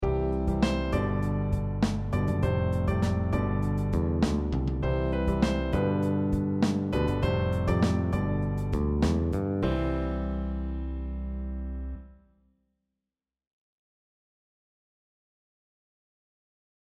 Piano Pop
These are two different melodies created in the level, with two vastly different styles. The building structures (trees, balloons, mountains) represent the chords in the accompaniment   Though the styles of the accompaniment are different, the chords progression of each are the same.
PianoPop_Bravura_Example.mp3